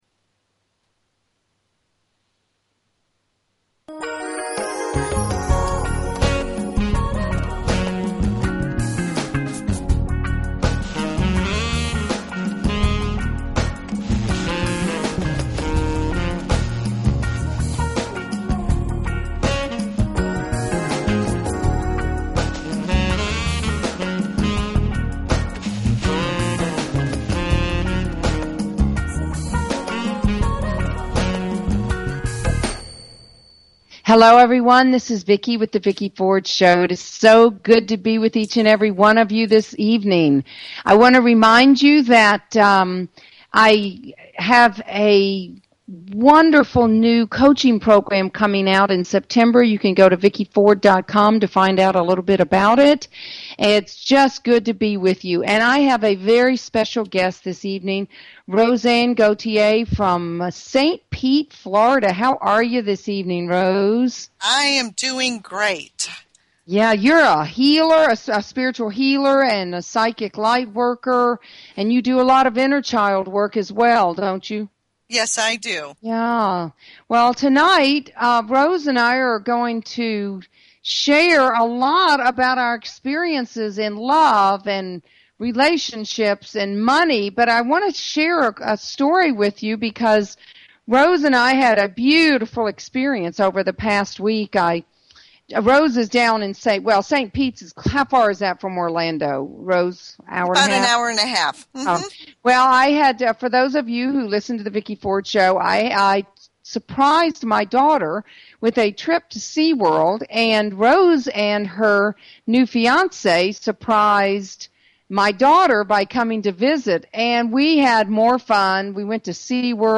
Talk Show Episode
welcomes callers for discussions as well as live readings